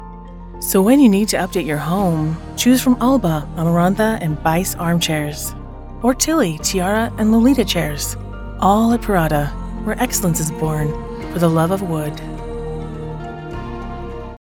Empathic, Sincere, and Direct - Broadcast ready in San Francisco Bay Area
Middle Aged
I sound like the boss who is looking out for you, a reliable friend who keeps you informed, or a voice of calm when you need it most.